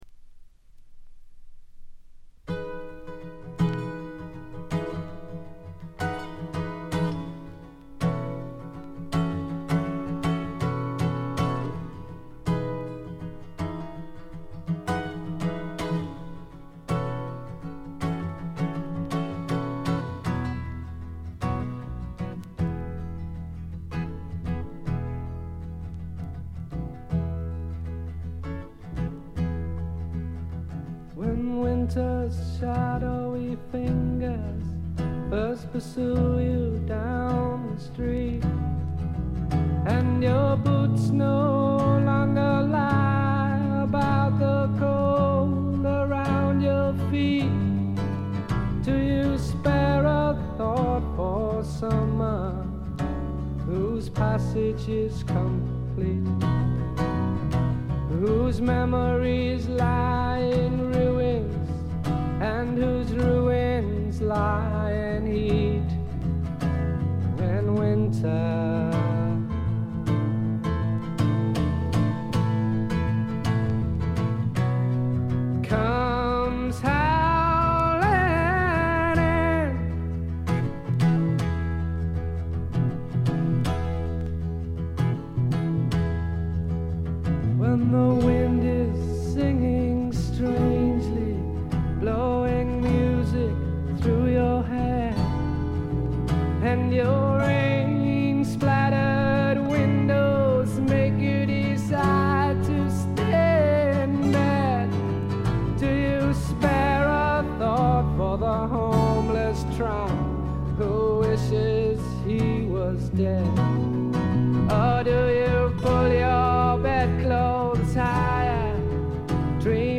わずかなノイズ感のみ。
英国フォークロック基本！
試聴曲は現品からの取り込み音源です。